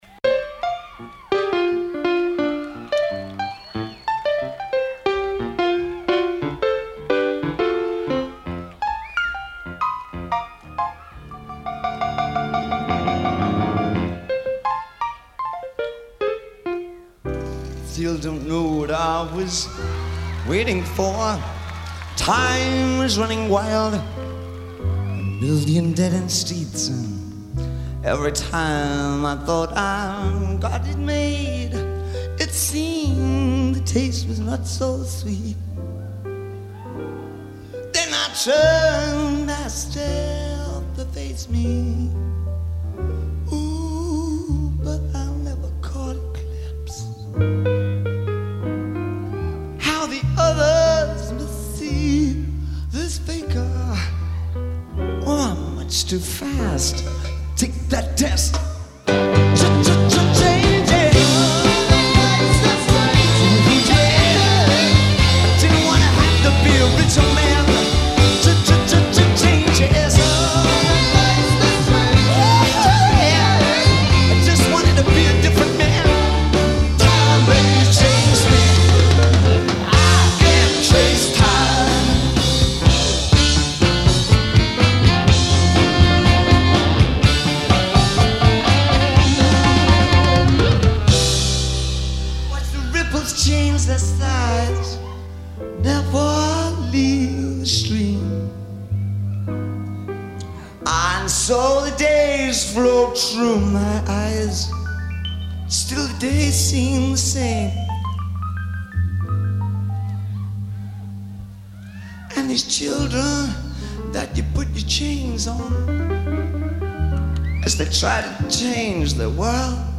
guitarist
sax